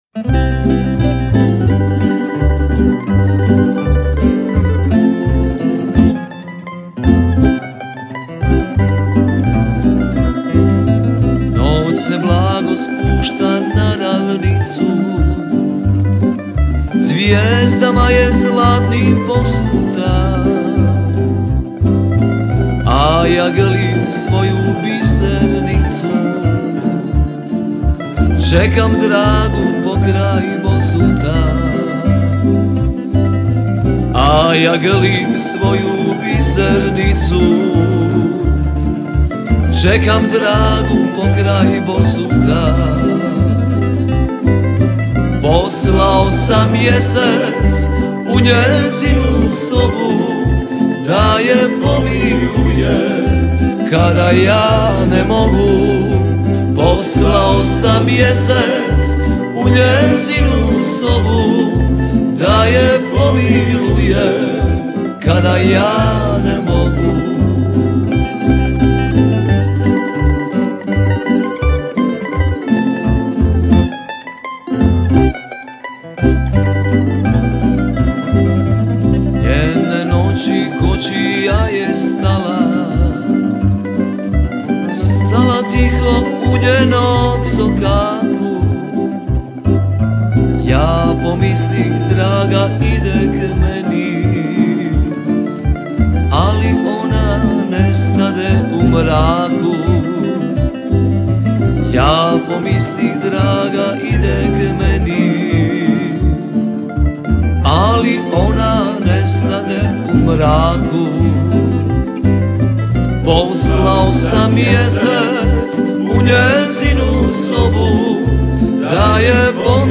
37. Festival pjevača amatera
Zvuci tamburice do kasnih noćnih sati odzvanjali su prepunom dvoranom vatrogasnog doma u Kaptolu.